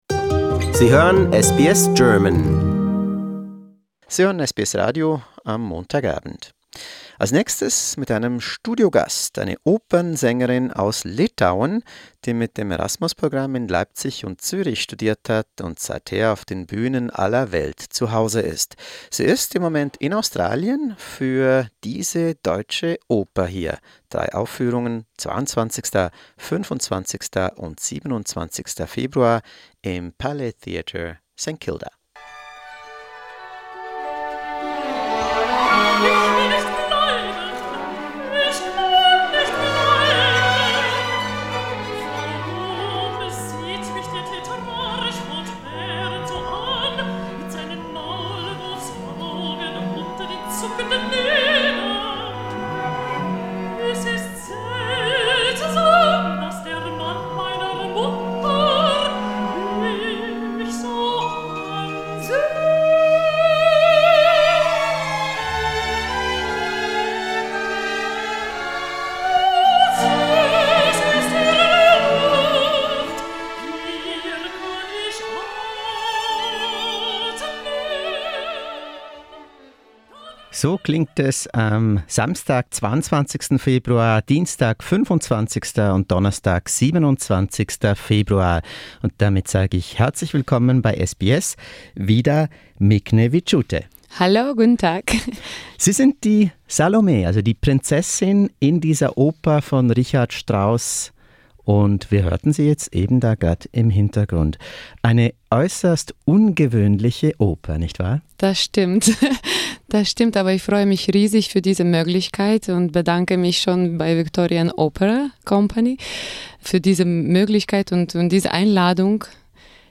im SBS-Funkhaus in Melbourne